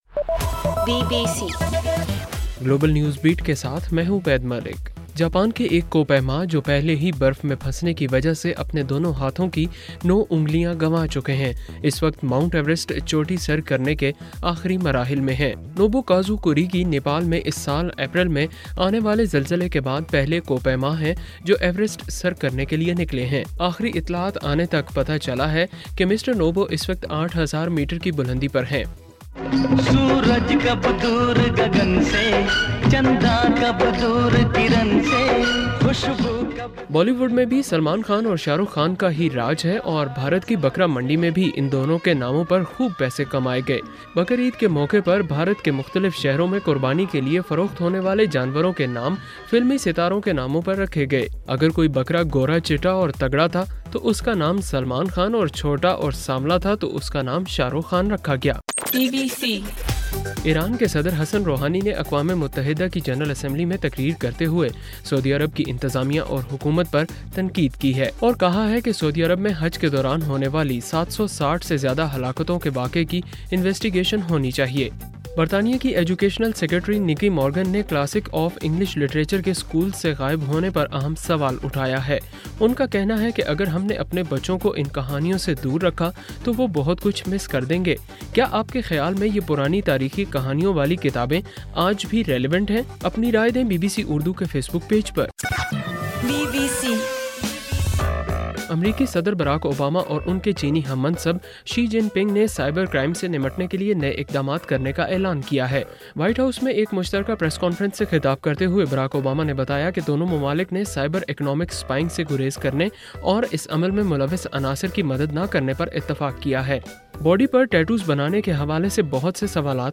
ستمبر 26: رات 11 بجے کا گلوبل نیوز بیٹ بُلیٹن